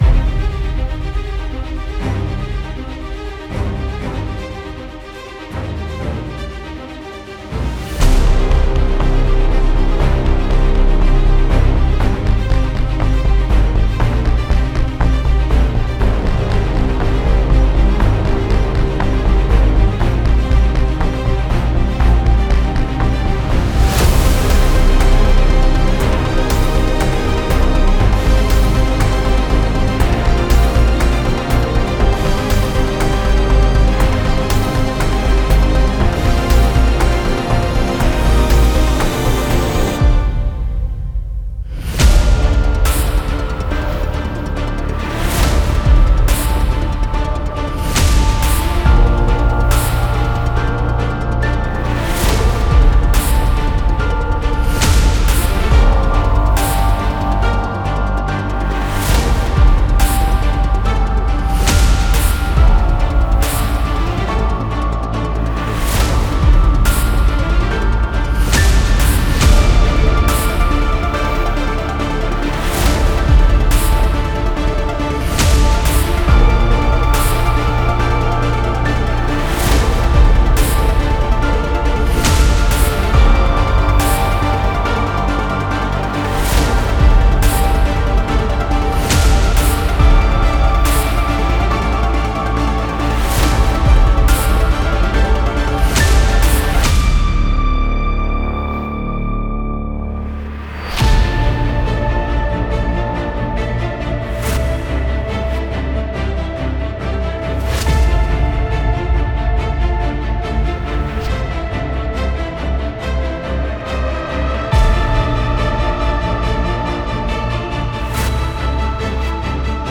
Genre:Filmscore
表現力豊かなオーケストラレイヤーと融合したモダンシンセ
進化するテクスチャ、繊細なモーション、ドラマティックなビルド
Genres: Sci-Fi · Drama · Cinematic
デモサウンドはコチラ↓